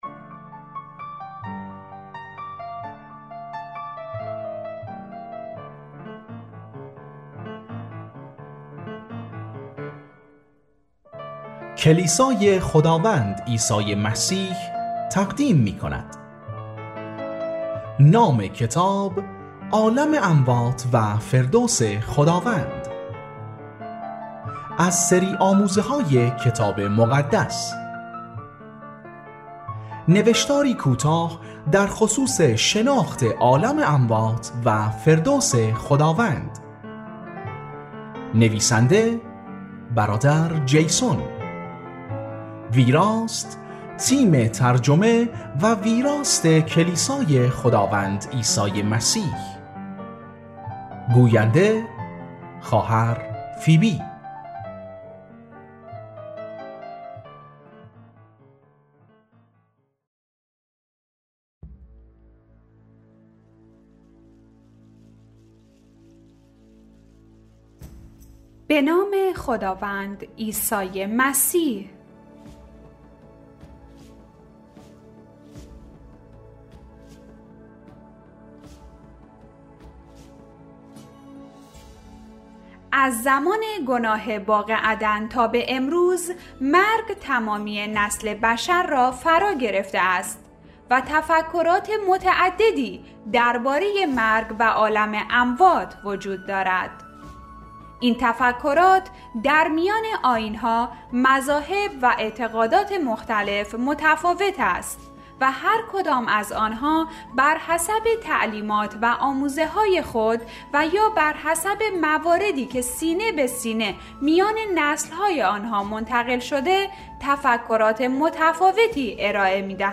عالم اموات، مکانی است که بنا بر تعالیم کتاب مقدّس، ارواحِ مردگان پس از جدایی از جسم به آن جا می‌روند. در این کتاب صوتی بیشتر به آن می‌پردازیم.